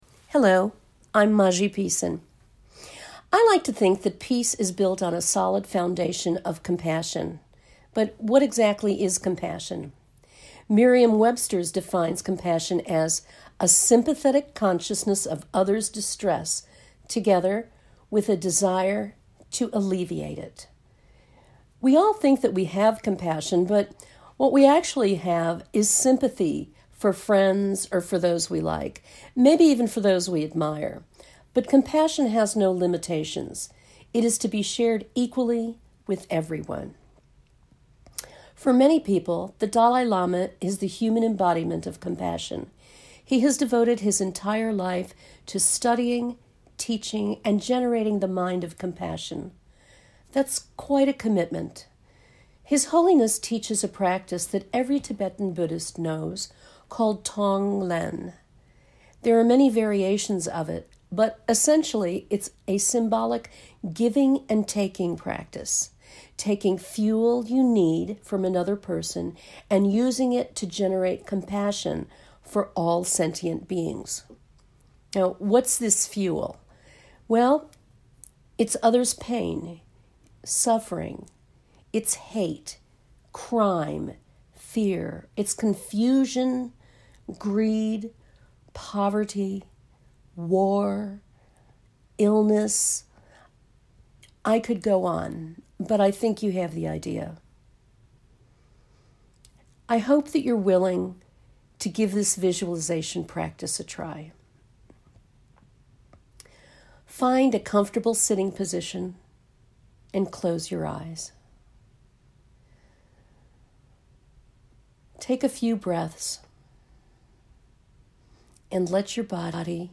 Tong Len Meditation for Compassion